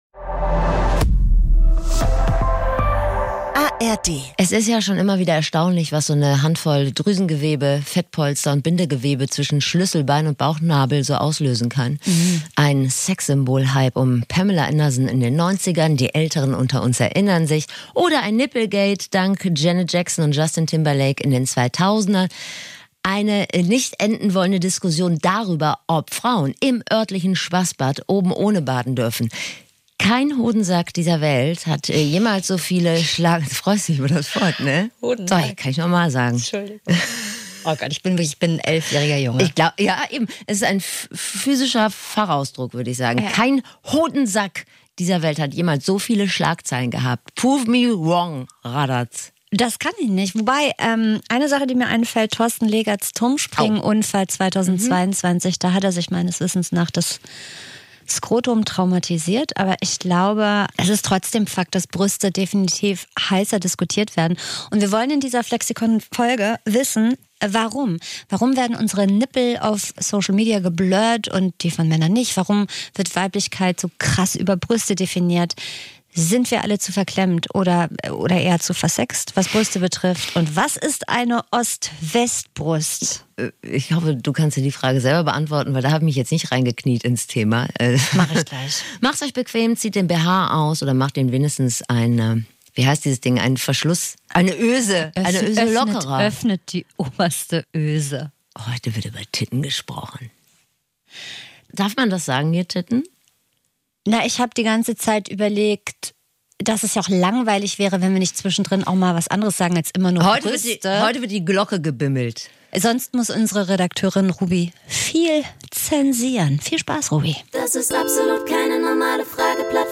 Dieser Laber-Podcast mit Bildungsauftrag versorgt Euch mit Klugscheißerwissen, mit dem Ihr ordentlich flexen könnt.